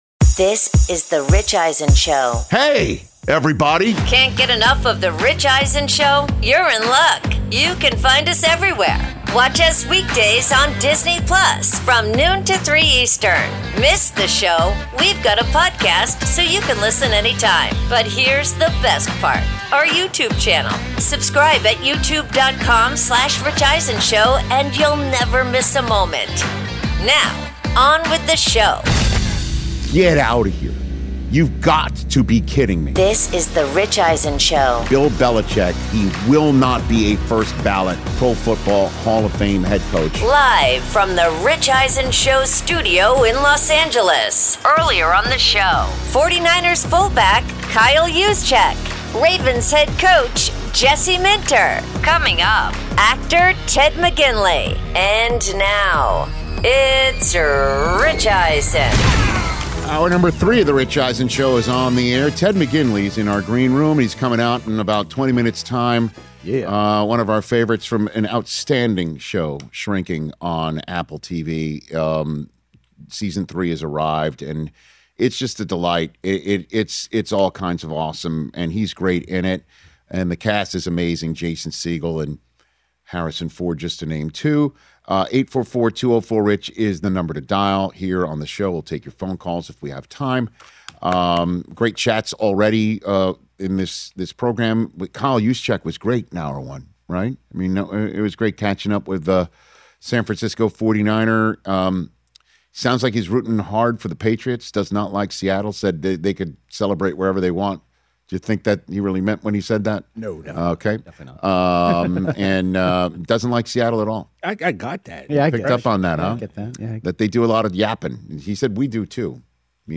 Hour 3: Power Ranking Super Bowl LX’s Top Storylines, plus Actor Ted McGinley In-Studio